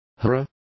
Complete with pronunciation of the translation of hearer.